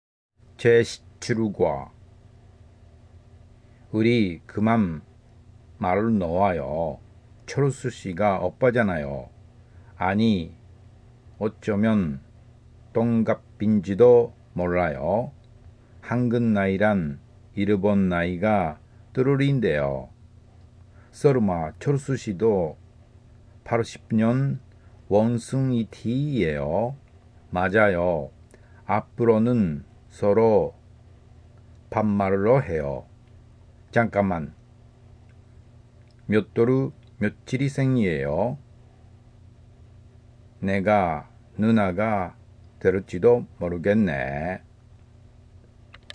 音読練習　『基礎から学ぶ韓国語　中級』　第11課から18課
音読練習しました。まだ慣れていませんが一度アップロードしておきます。
自信なさそうですねぇ。無理して読んでますね。